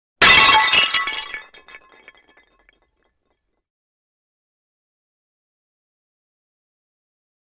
Pottery | Sneak On The Lot